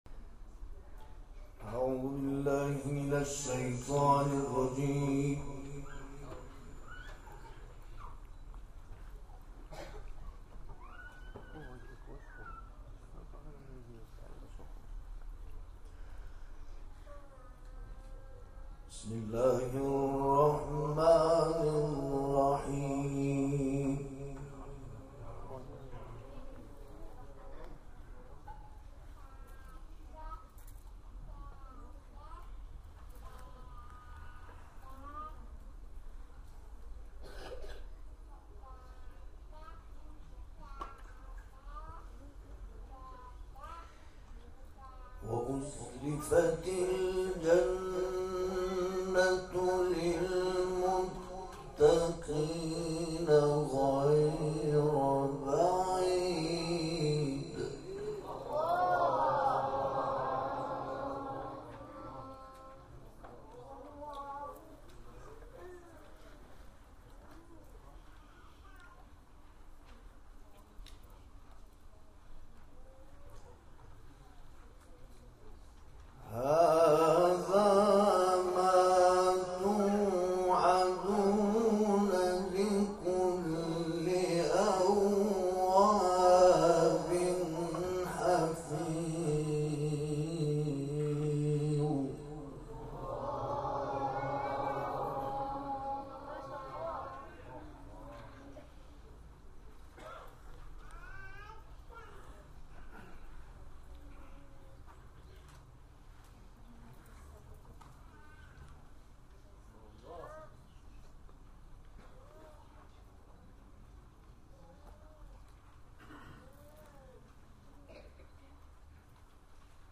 گروه جلسات و محافل: محفل انس با قرآن کریم به مناسبت حلول ماه ربیع الاول، صبح امروز 12 آذرماه با تلاوت دو قاری ممتاز و بین المللی برگزار شد.